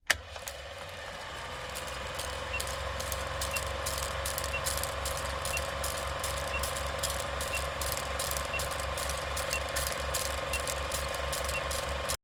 دانلود آهنگ ثانیه شمار 6 از افکت صوتی اشیاء
جلوه های صوتی